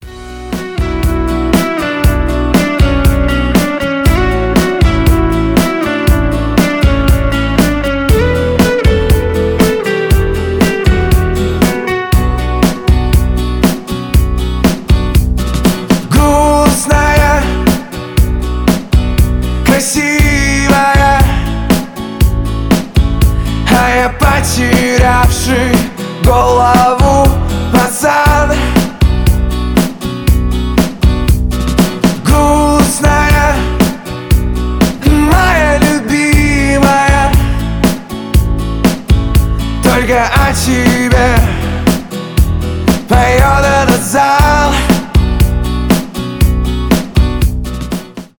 рок
гитара